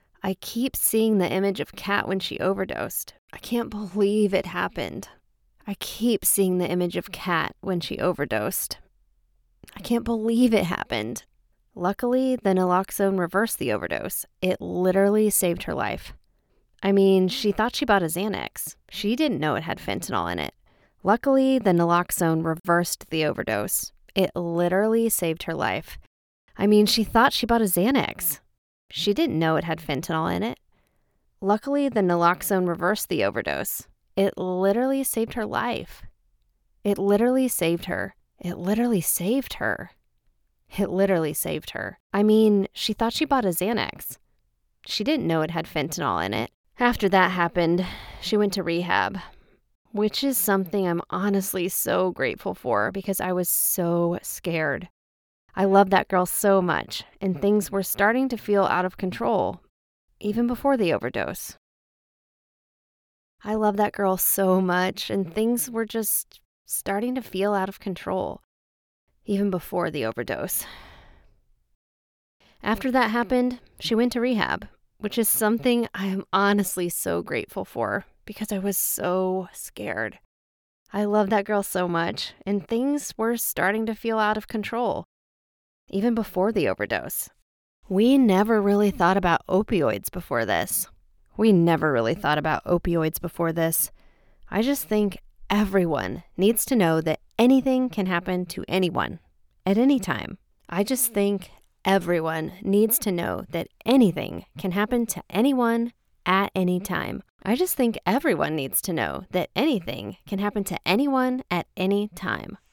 Female
Yng Adult (18-29), Adult (30-50)
My voice is fresh, friendly, and relatable—perfect for brands that want to sound real, not rehearsed.
I bring a youthful energy that feels genuine, approachable, and easy to connect with.
Think "girl next door" meets modern mom—warm, trustworthy, and effortlessly conversational.
My style is natural and engaging with a youthful tone.
Commercial Demo; Fresh
Explainer Sample